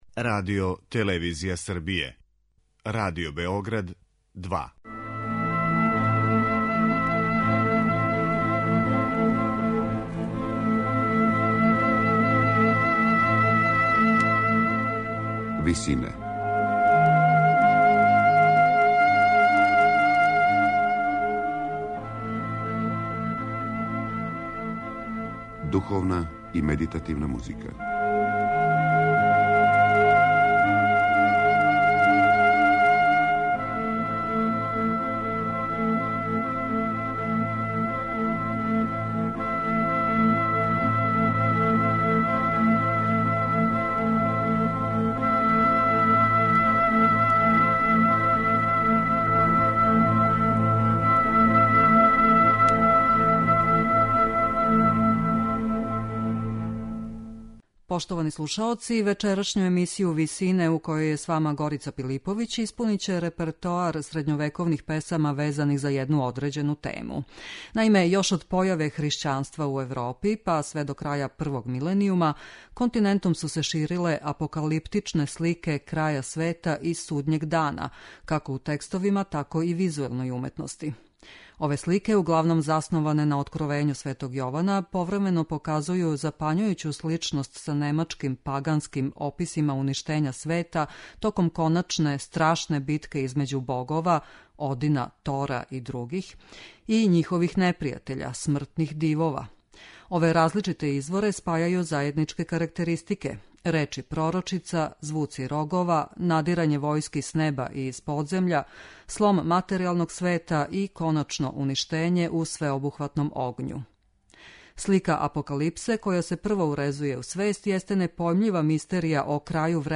Духовна музика
На крају програма, у ВИСИНАМА представљамо медитативне и духовне композиције аутора свих конфесија и епоха.